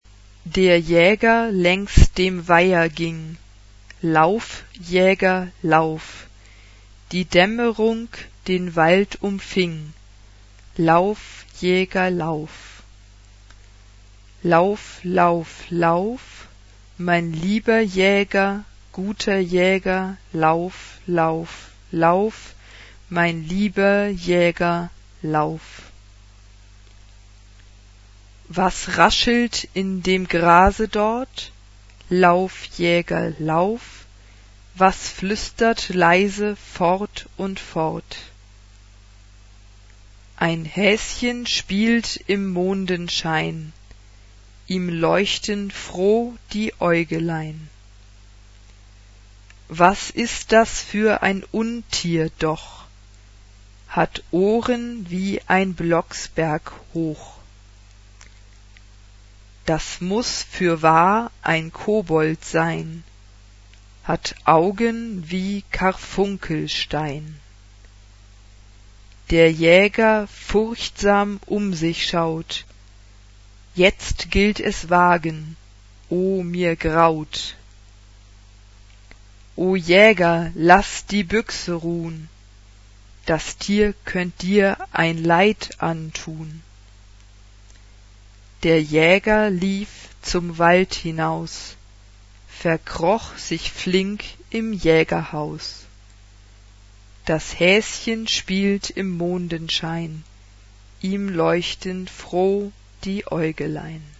Choir. Folk music.
Instrumentation: Piano (1 instrumental part(s))
Tonality: F major